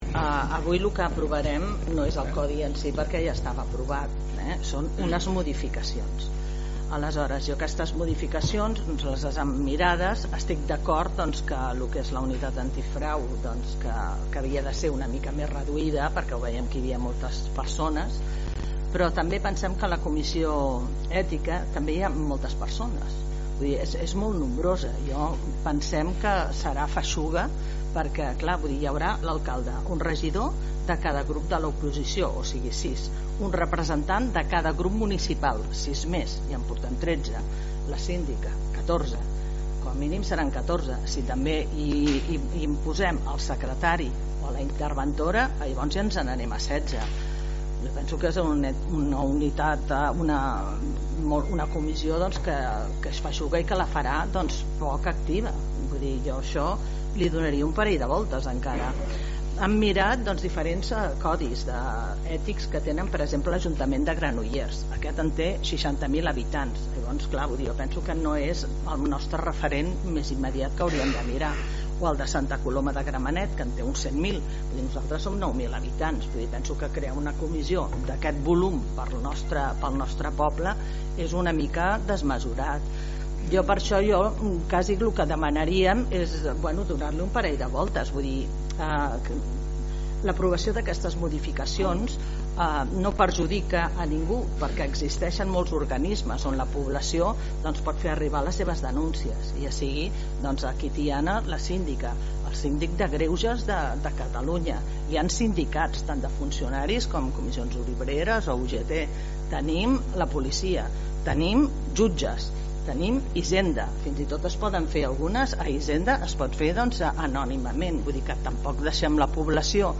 La portaveu de Junts per Catalunya Tiana, Montse Torres, també estava a favor de la despolitització de la Unitat antifrau, tot i que es va abstenir perquè considerava que la Comissió ètica estava formada per masses persones: